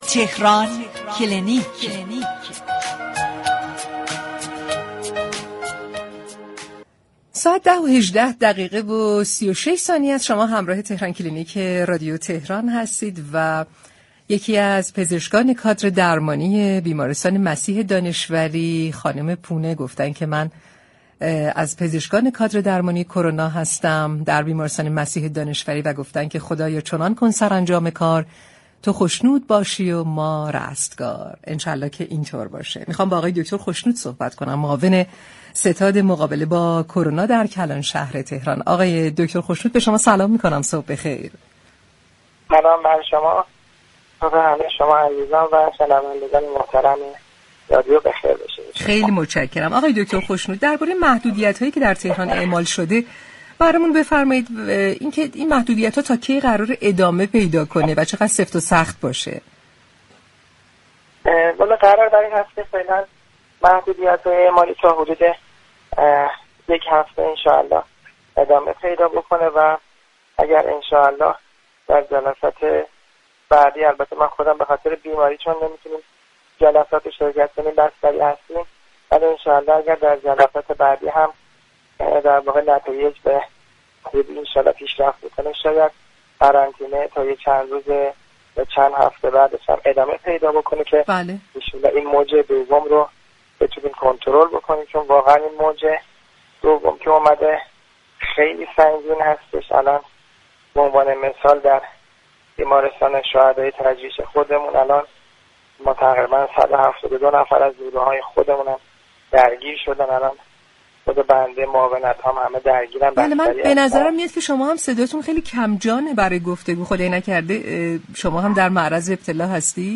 معاون ستاد مبارزه با كرونای تهران در گفتگو با رادیو تهران گفت: موج دوم كرونا بسیار شدیدتر از موج اول است و آمار مبتلایان كادر درمانی به شدت افزایش یافته است.